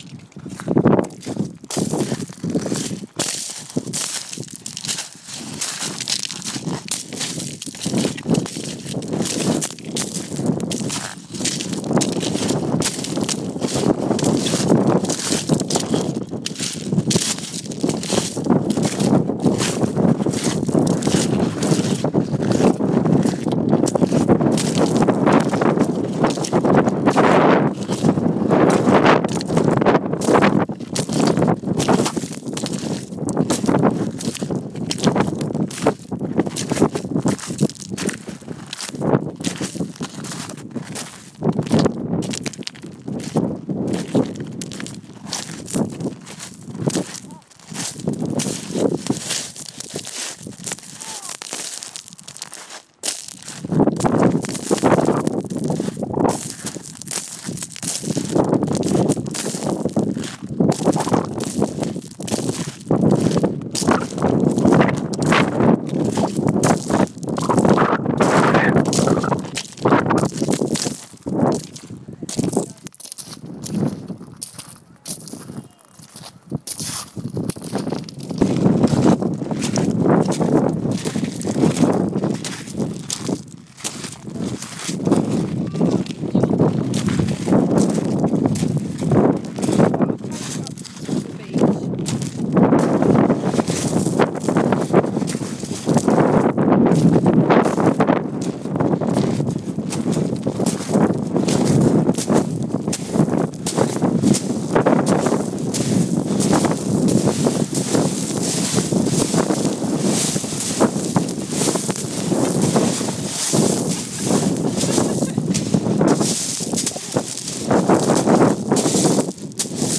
Walking on pebbles